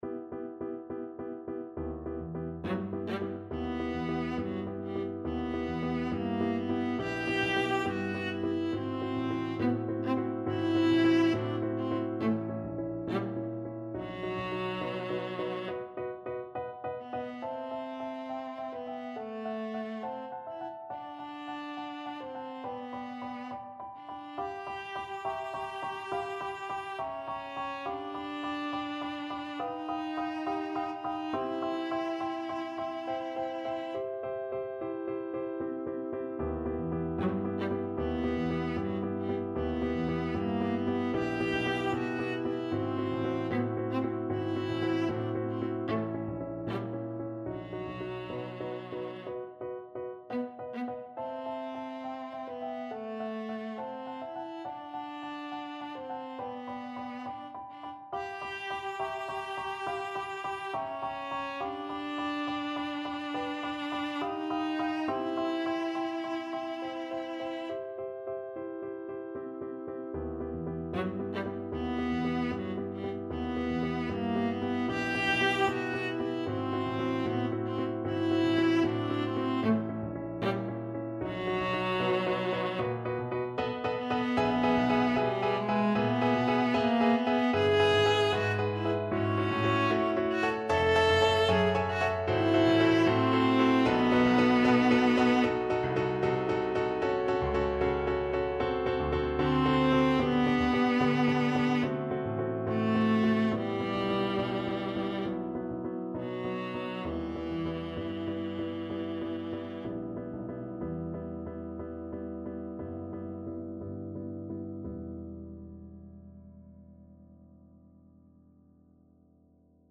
Classical Brahms, Johannes Liebestreu, Six Songs, Op.3 Viola version
Viola
E minor (Sounding Pitch) (View more E minor Music for Viola )
=69 Sehr langsam
Classical (View more Classical Viola Music)